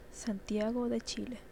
Santiago (/ˌsæntiˈɑːɡ/, US also /ˌsɑːn-/;[3] Spanish: [sanˈtjaɣo]), also known as Santiago de Chile (Spanish: [san̪ˈtja.ɣo ðe ˈtʃi.le]
StgoEsp.ogg.mp3